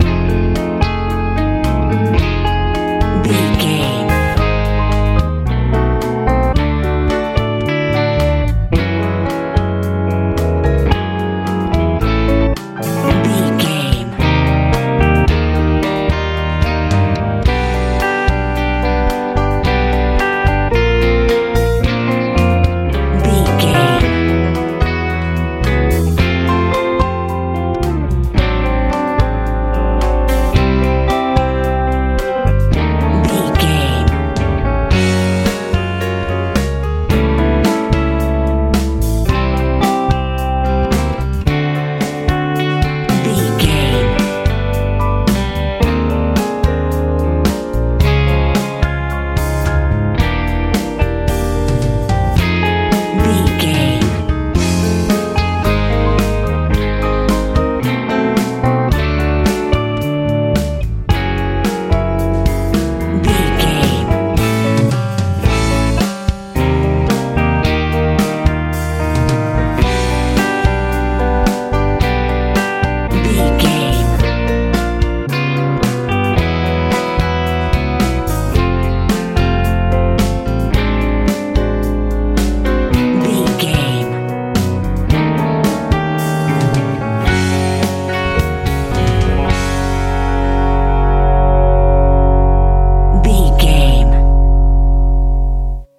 new age feeling music
Ionian/Major
light
piano
electric guitar
bass guitar
drums
bright
calm
cheerful/happy
motivational